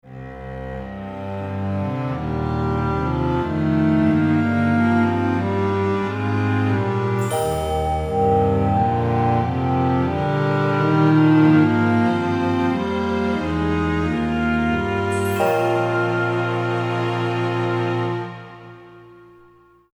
Drama